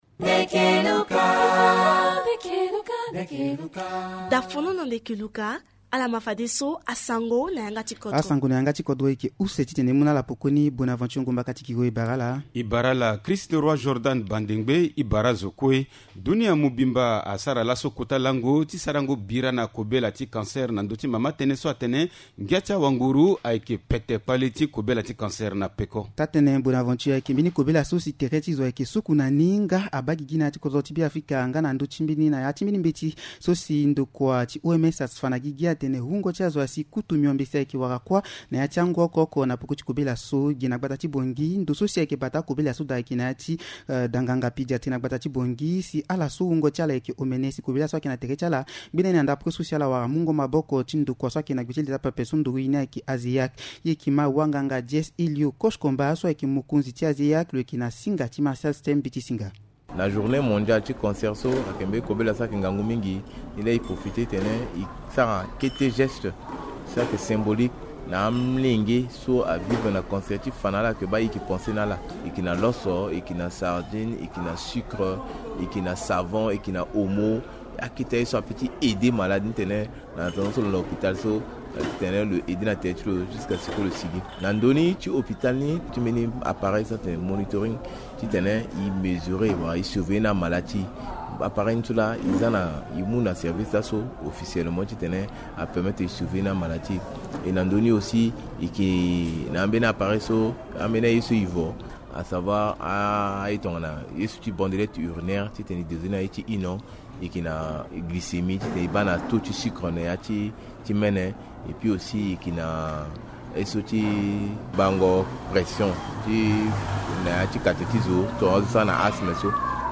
Journal en sango